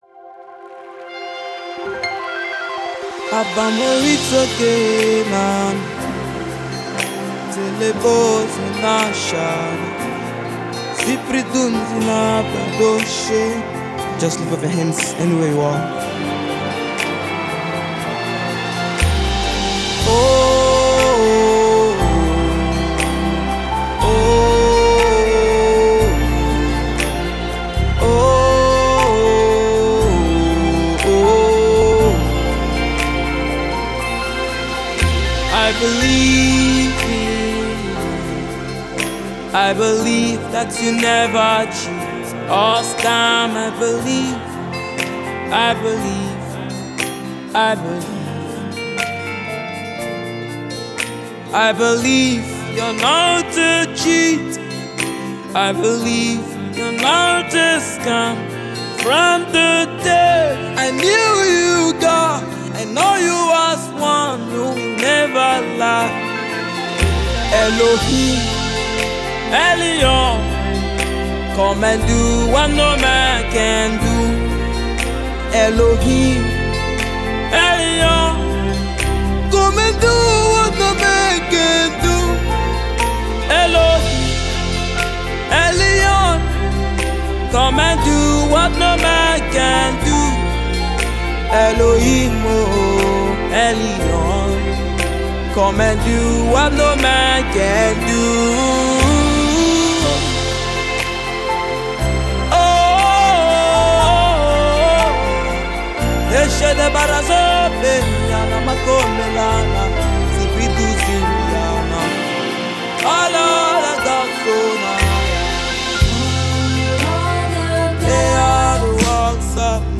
faith-filled anthem